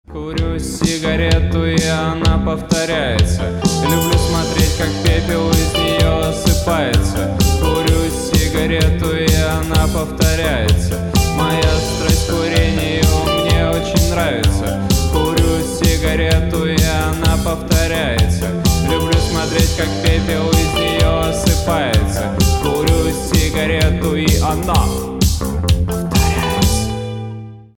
• Качество: 192, Stereo
блатные